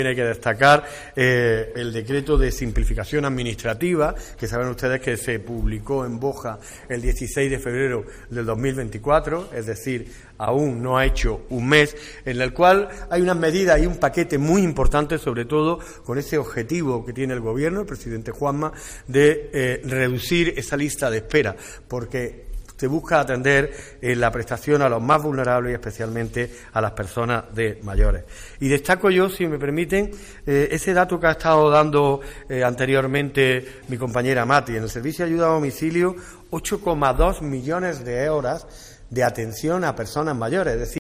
Antonio Granados, Delegado del Gobierno andaluz
Estos son algunos de los datos ofrecidos por el delegado del Gobierno, Antonio Granados, y la delegada de Inclusión Social, Juventud, Familias e Igualdad, Matilde Ortiz, en el transcurso de una rueda de prensa en la que se abordó el estado de la Dependencia en Granada, “una provincia que ha experimentado un incremento del 57,6% en el número de personas atendidas en dependencia, 14.725 personas beneficiarias más en la actualidad, en relación a finales de 2018, año en el que se atendían a 25.558 personas”, según Granados